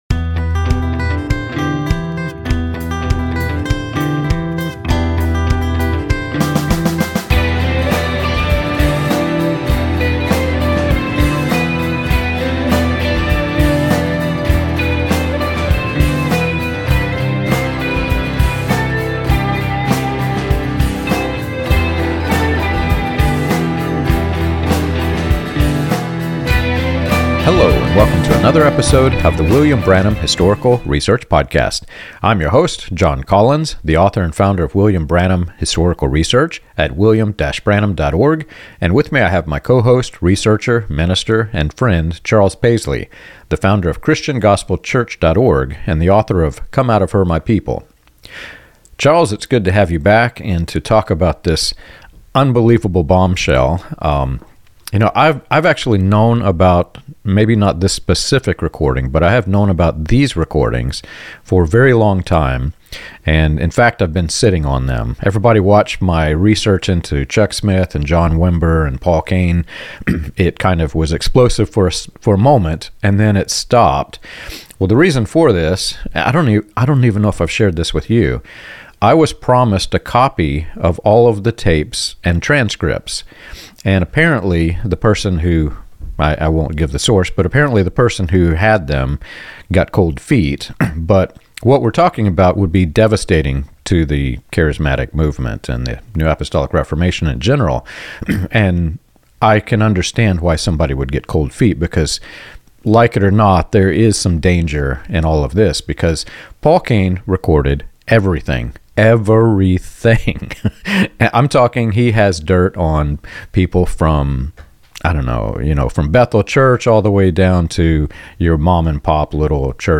The conversation explores how recordings, private confessions, and reputation management became tools for enforcing silence, shaping narratives, and protecting movements at critical moments.